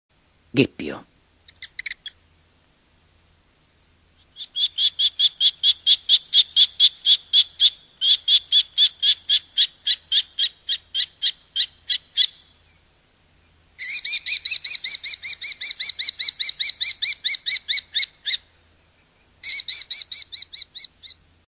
Gheppio – Falchèto
Falco tinnunculus
Il richiamo in vicinanza del sito di nidificazione è uno stridulo e acuto ‘kii-kii-kii-kii’.
Gheppio.mp3